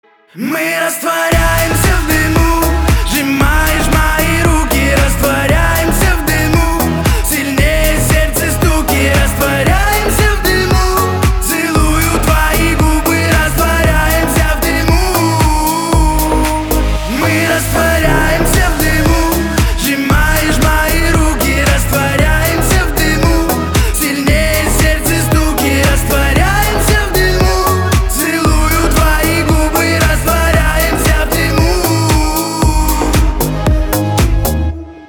• Качество: 320, Stereo
поп
громкие